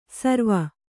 ♪ sarva